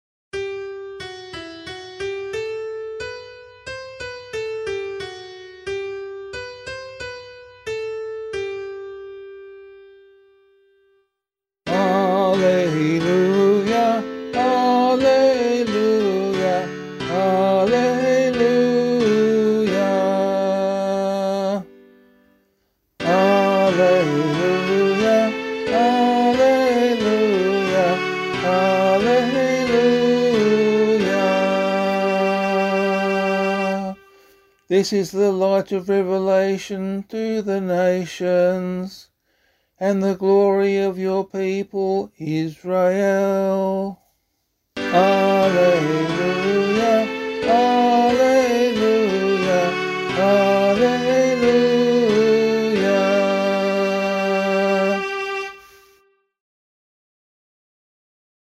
Gospelcclamation for Australian Catholic liturgy.
133 Presentation Gospel [LiturgyShare E - Oz] - vocal.mp3